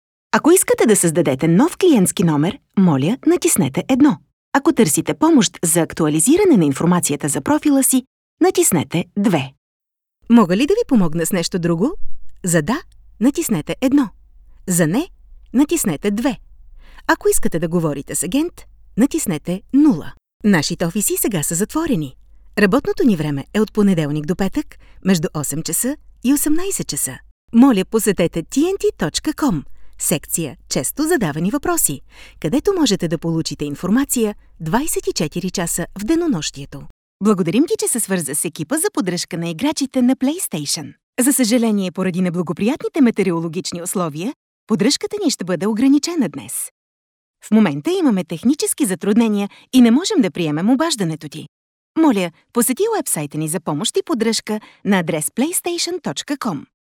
Female
Yng Adult (18-29), Adult (30-50)
Phone Greetings / On Hold